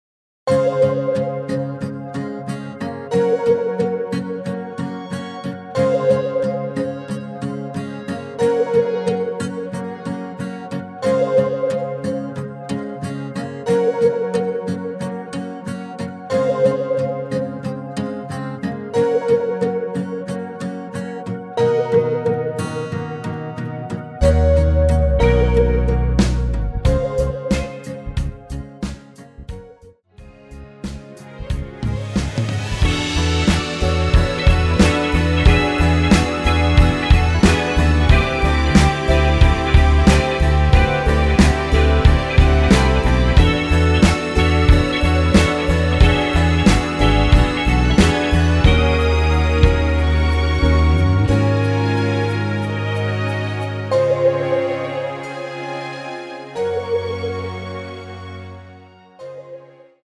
여성분이 부르실수 있도록(+3)키로 제작 하였습니다.(미리듣기 참조)
C#
앞부분30초, 뒷부분30초씩 편집해서 올려 드리고 있습니다.
중간에 음이 끈어지고 다시 나오는 이유는